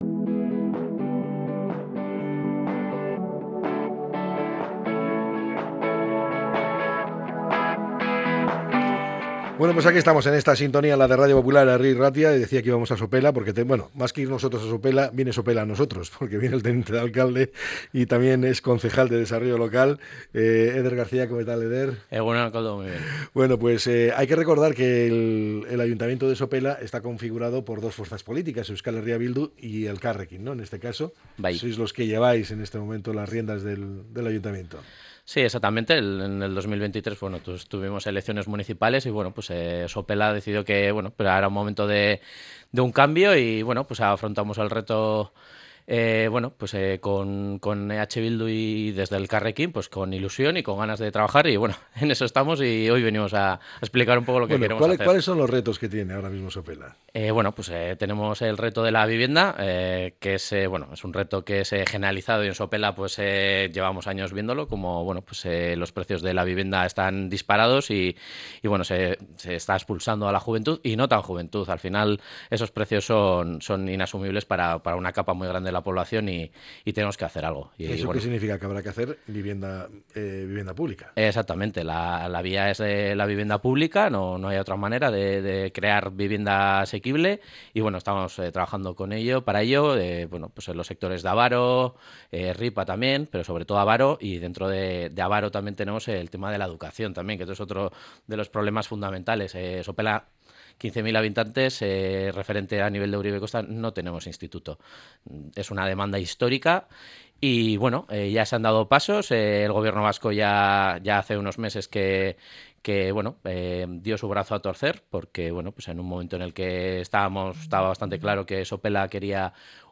Entrevista al Teniente de Alcalde y Concejal de Desarrollo Local de Sopela, Eder García